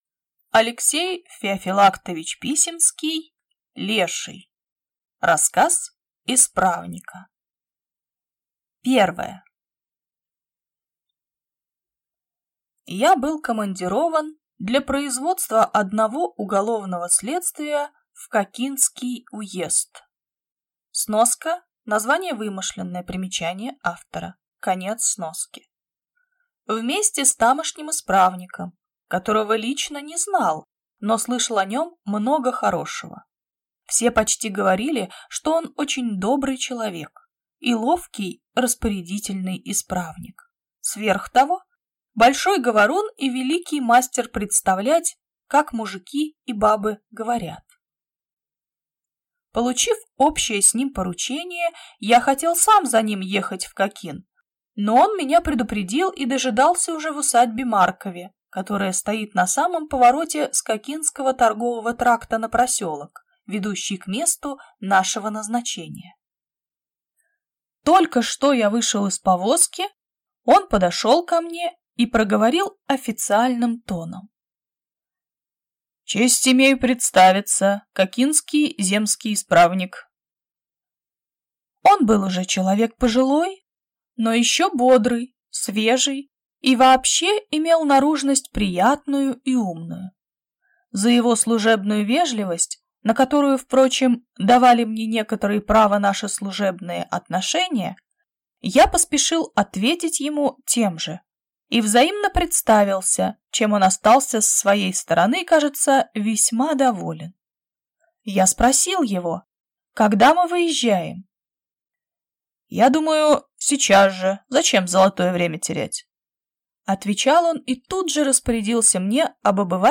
Аудиокнига Леший | Библиотека аудиокниг